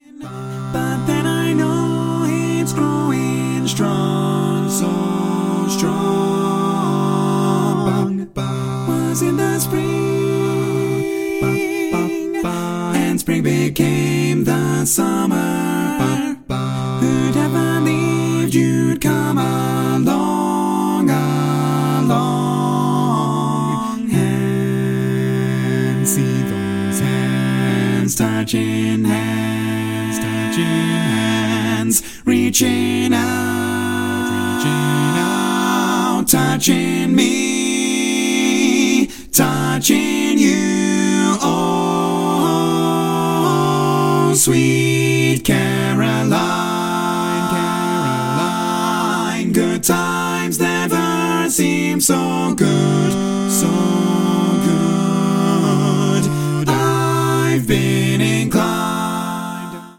Full mix